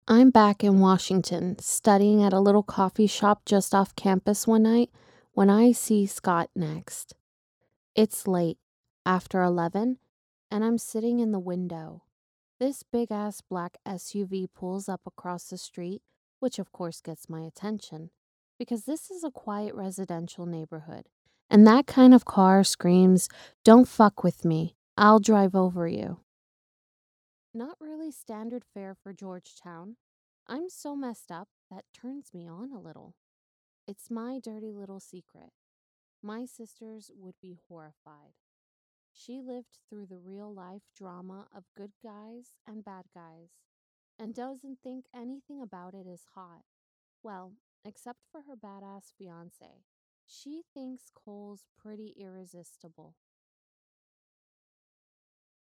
ringy-hiss in audio HELP PLEASE :)
Listen to the before and now the after....BIG DIFFERENCE! Chain of effects: Legacy>Garageband>Podcasting>Female Narrator Effect plugins I show in use: Channel EQ Noise Gate Master Echo In the clip I even went as far as "normalizing" a high gain (low voice) section and magically there still was no hiss!